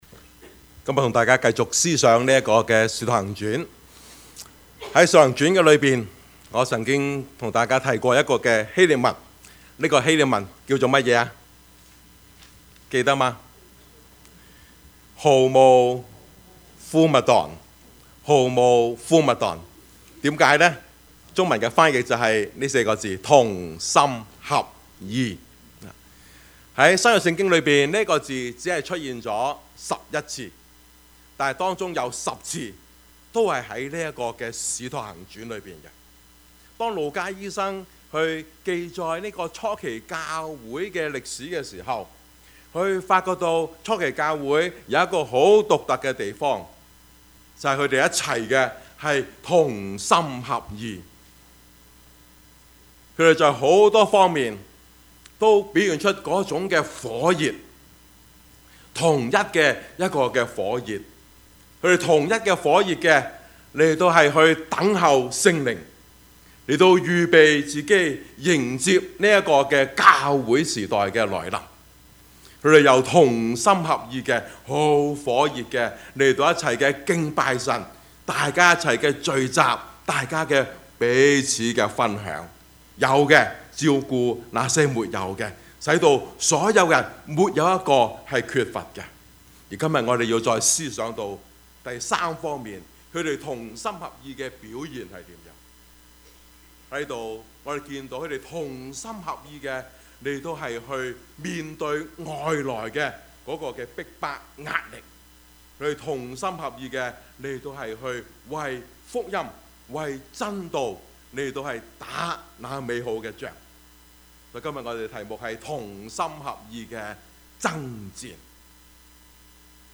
Service Type: 主日崇拜
Topics: 主日證道 « 主啊!